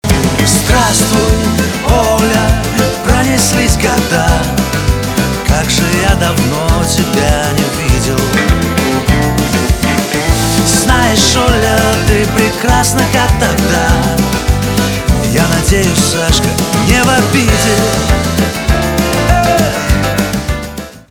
поп
пианино , гитара , барабаны